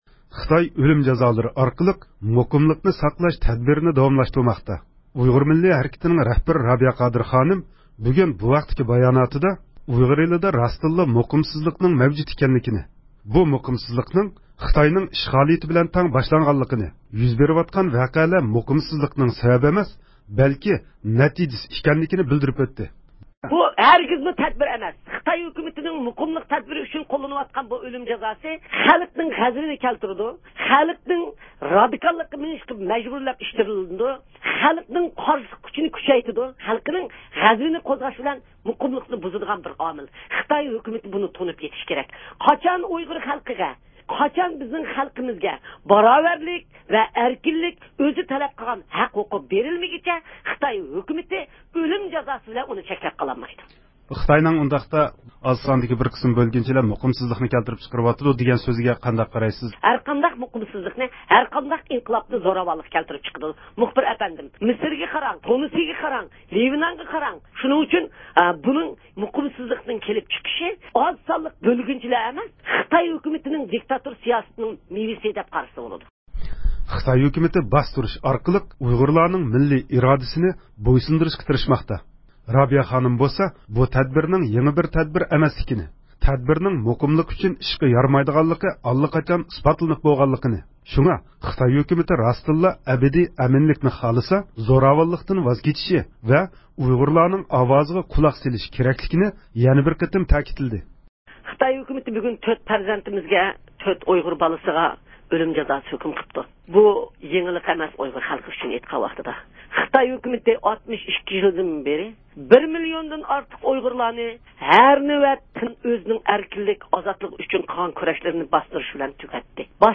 دۇنيا ئۇيغۇر قۇرۇلتىيىنىڭ رەئىسى رابىيە قادىر خانىم، بۈگۈن بۇ ھەقتە رادىئومىزغا بايانات بېرىپ، خىتاي ھۆكۈمىتىنىڭ بولۇپ ئۆتكەن ئاچچىق ۋەقەلەردىن ساۋاق ئالمايۋاتقانلىقىنى ئەيىبلىدى.